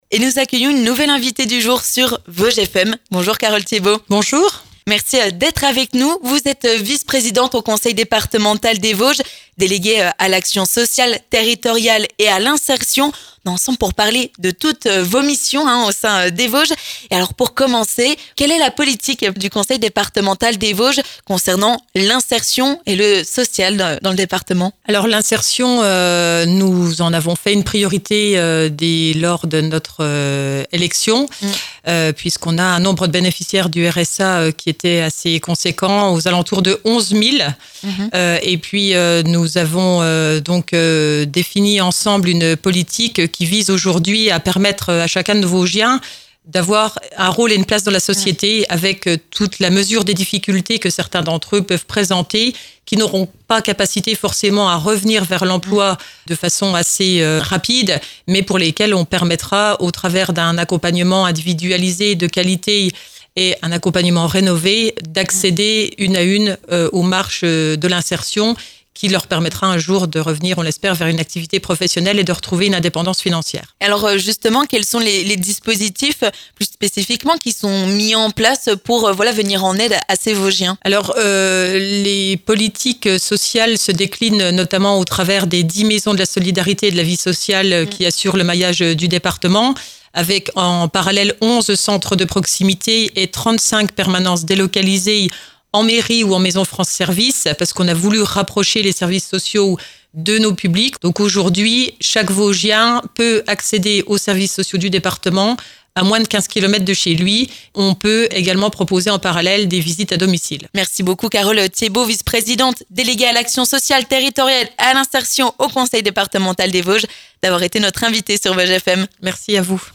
L'invité du jour
On fait le point sur la politique sociale sur le territoire avec notre invitée du jour, Carole Thiébaud, vice-présidente du Conseil départementale des Vosges déléguée à l'action sociale, territoriale et à l'insertion.